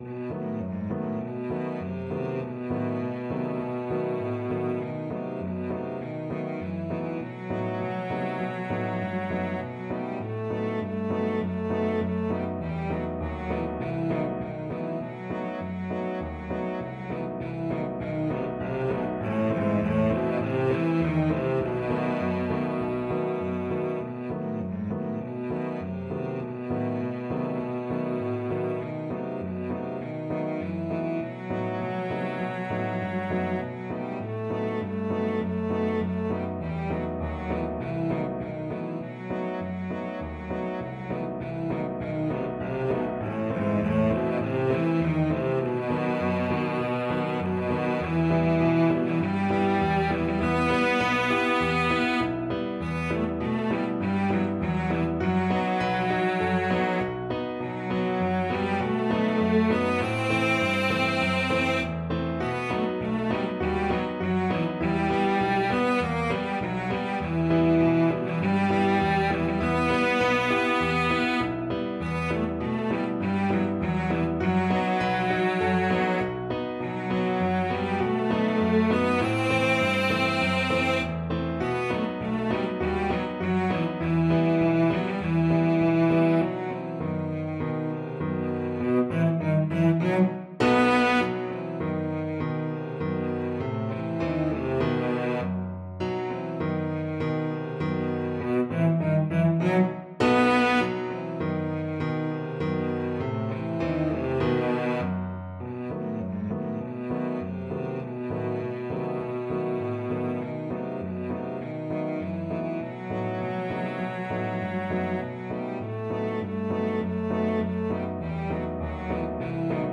Cello
Traditional Music of unknown author.
4/4 (View more 4/4 Music)
E minor (Sounding Pitch) (View more E minor Music for Cello )
Moderato
Traditional (View more Traditional Cello Music)